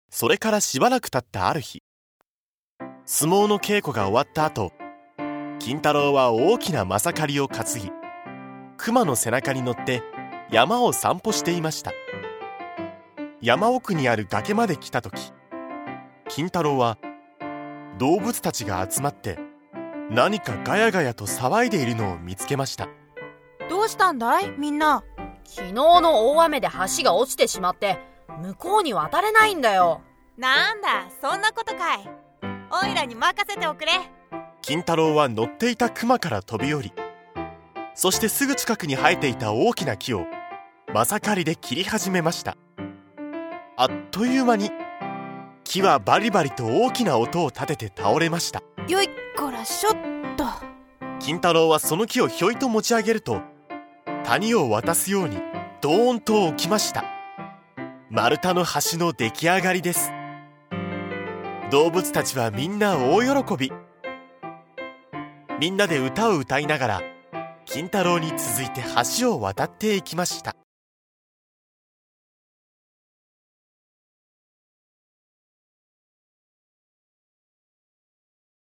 大人も子どもも一緒になって、多彩なキャストと、楽しい音楽でお楽しみ下さい。
大人も子供も楽しめる童話オーディオブックを、多彩なキャストとBGMでお届けします。